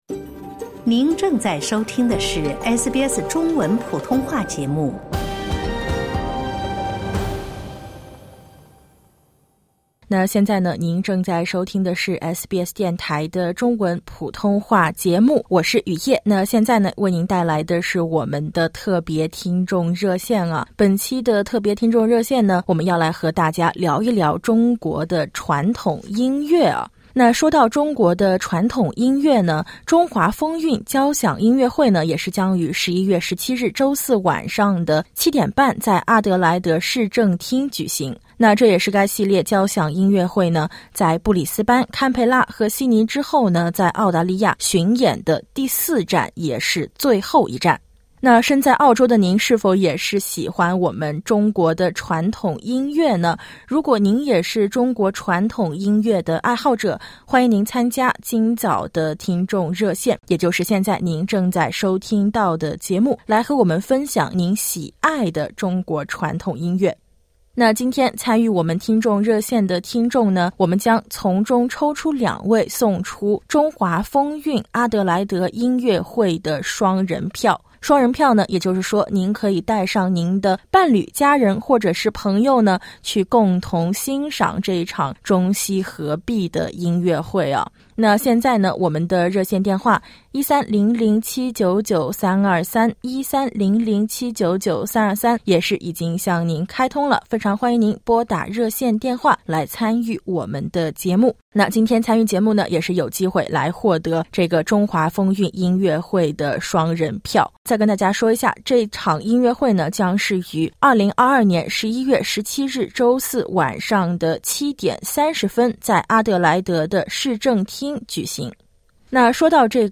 【特别听众热线】享中国传统音乐 品“中西合璧”之音